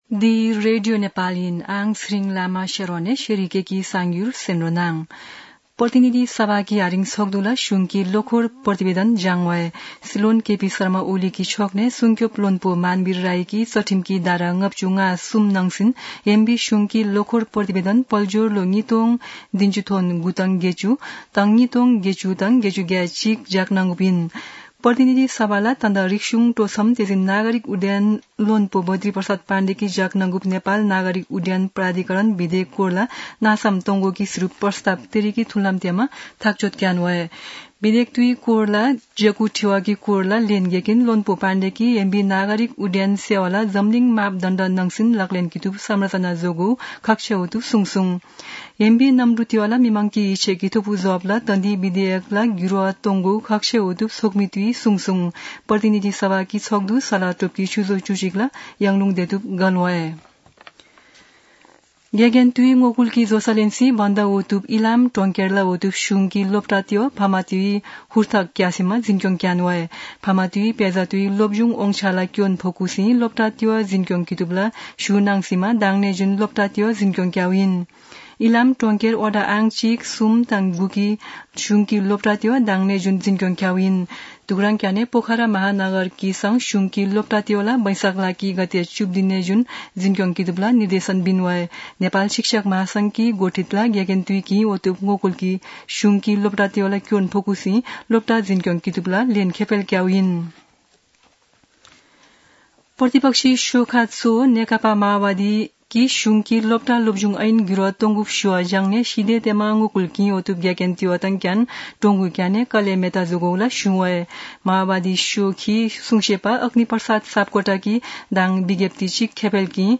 शेर्पा भाषाको समाचार : १५ वैशाख , २०८२